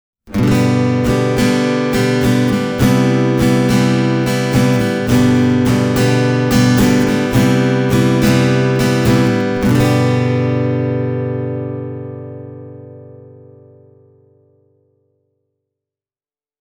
Testikitara soi täyteläisesti, mutta tarkasti, ja se tarjoaa aimon annoksen tuoreutta ja helinää diskantissa.
Hieman plektrasoittoa AKG:lla nauhoitettuna:
…ja sama pätkä Purecoustics-järjestelmän kautta: